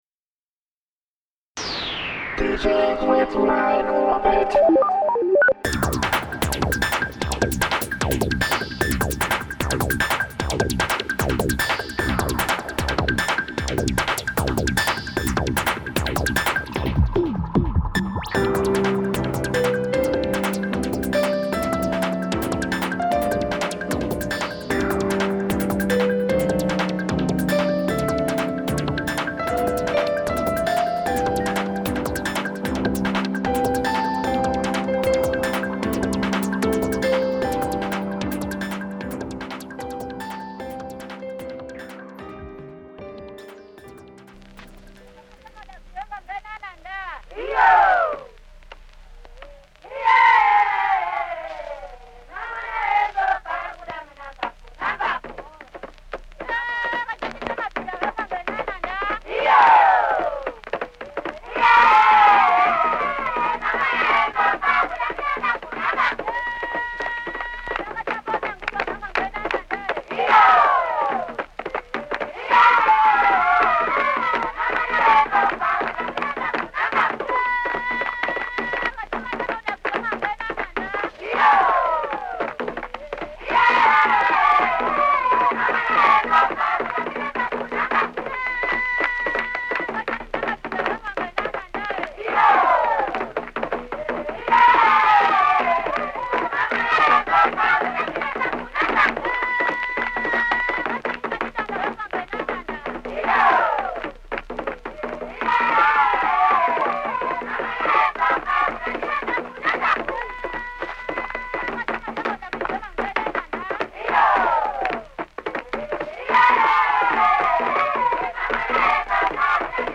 Female Chorus, Drums